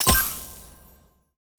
Special & Powerup (4).wav